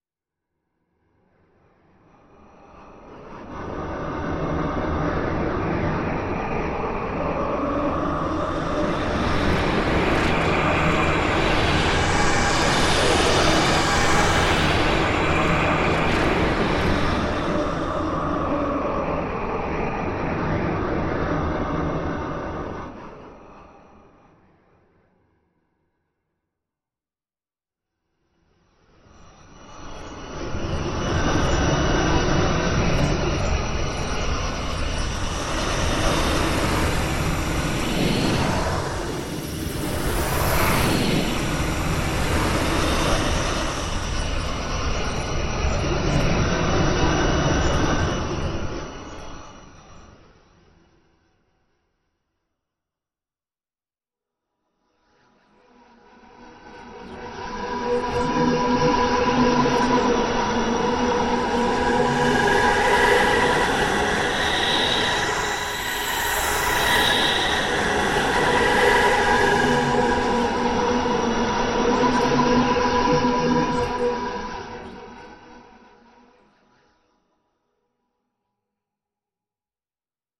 Звуки аномалий
Звук Серия звуков с экстраординарными аномалиями (для монтажа) (01:21)